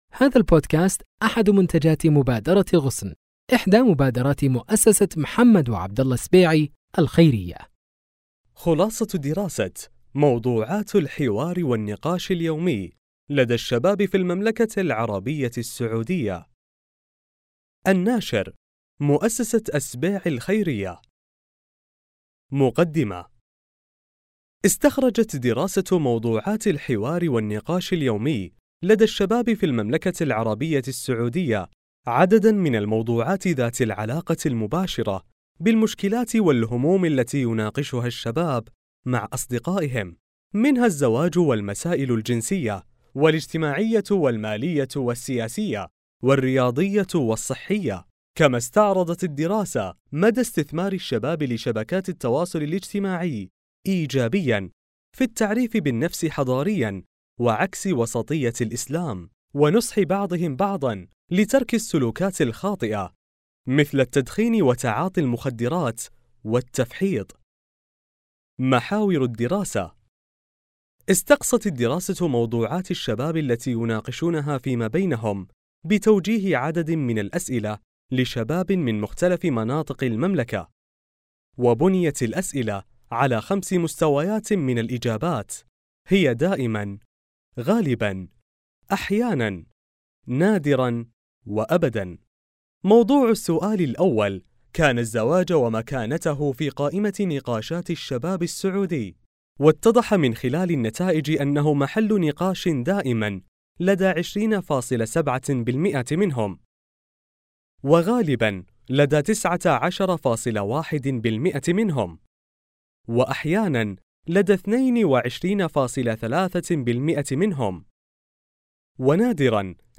موضوعات الحوار والنقاش اليومي لدى الشباب في المملكة العربية السعودية احصل على الخلاصة بالصيغة المناسبة PDF TXT EPUB KINDLE MP3 إستمع إلى الكتاب الصوتي